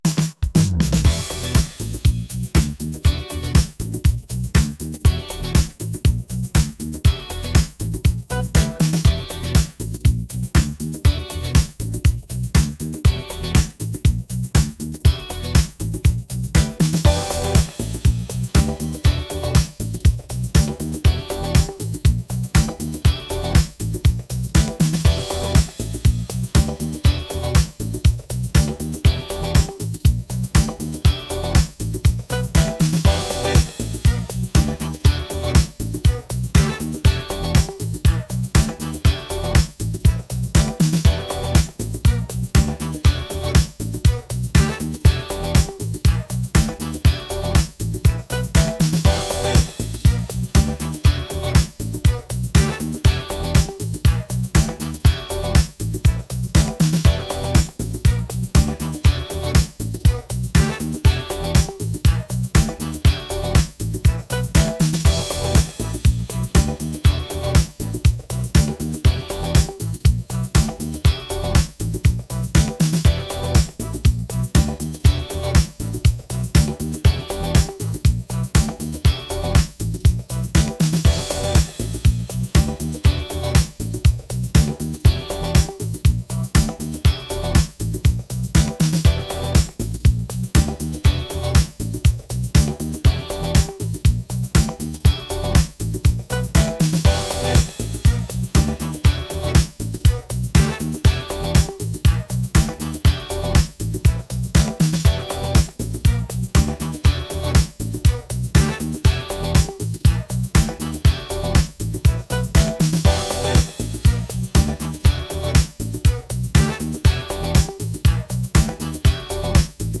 funky | upbeat